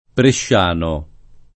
[ prešš # no ]